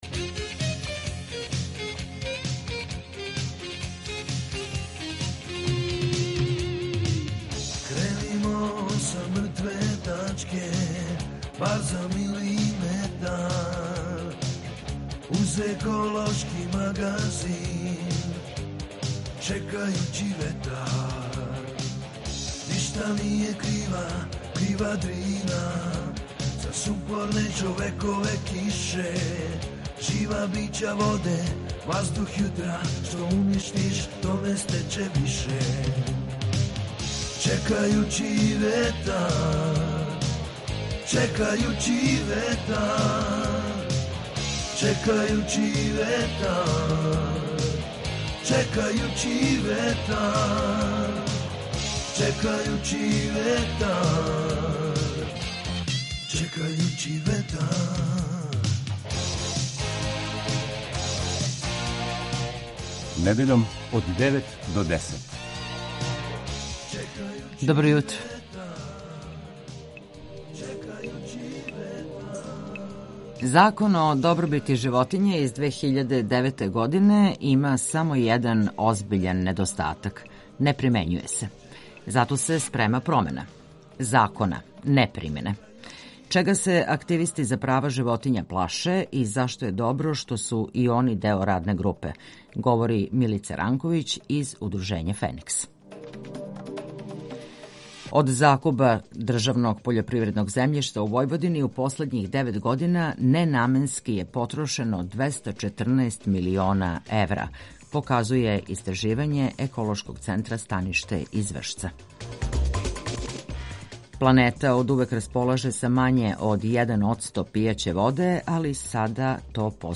ОВДЕ Чекајући ветар - еколошки магазин Радио Београда 2 који се бави односом човека и животне средине, човека и природе.